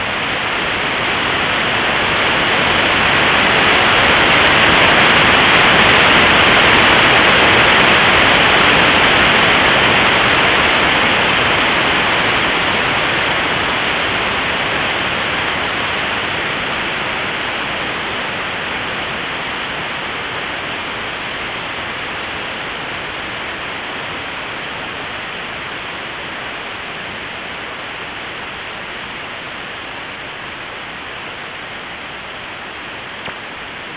Most of the second burst on this spectrogram was captured on audio at the Big Island Radio Observatory.